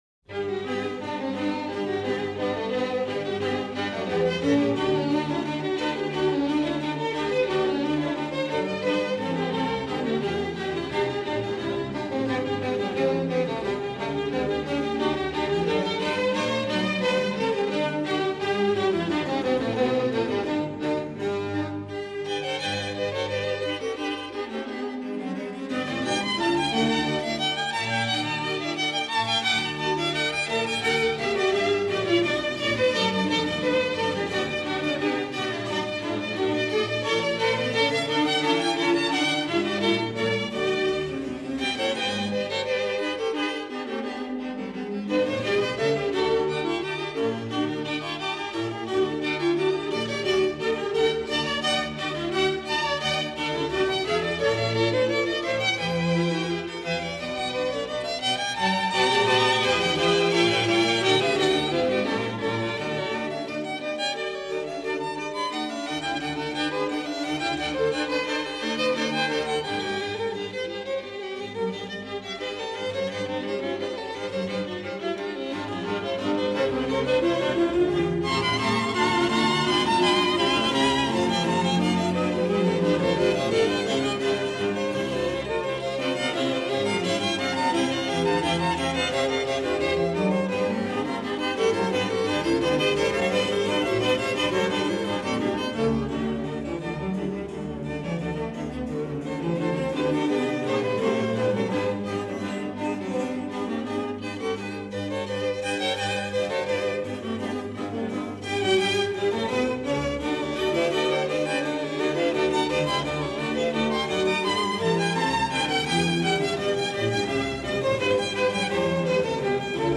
• Жанр: Джаз